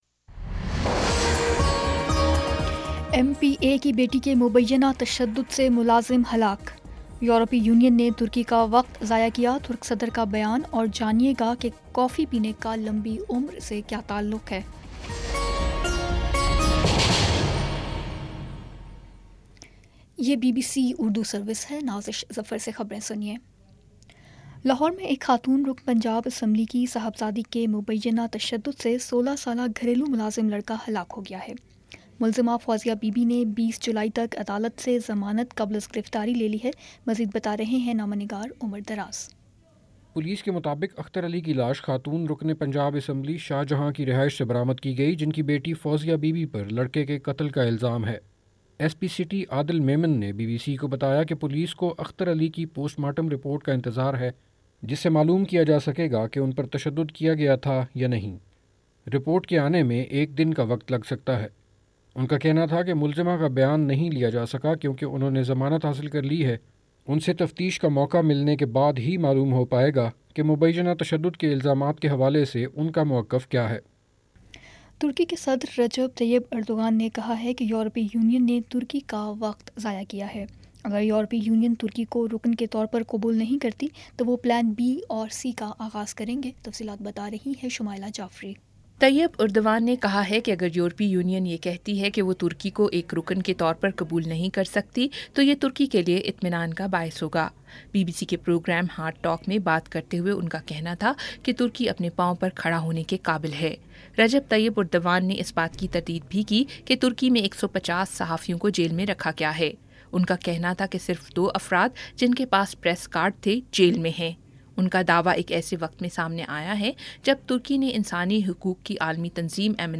جولائی 12 : شام چھ بجے کا نیوز بُلیٹن